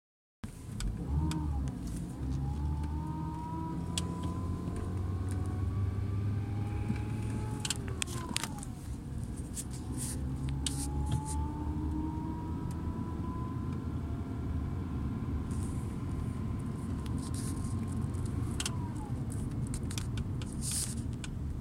Ljud från min Volvo S60 (2002) när jag kör.
Jag har en Volvo S60 från 2002 som har ett konstigt ljud när jag kör.
Jag spelade in det på min mobil inifrån bilen när jag var ute och körde (helt normalt).
Jag har inte varvat motorn onormalt eller liknande utan detta är vid normal körning.
Bör tilläggas är att ljudet är kopplat till varvtalet och ökar när varvtalet på motorn ökar.
Det är samma ljud oavsett om jag är i friläge eller kör som vanligt.
Högre varvtal = mer ljud.
car_sound.m4a